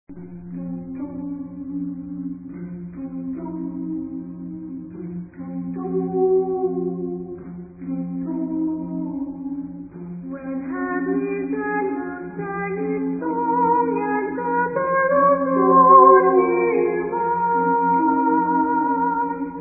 Tonality: F minor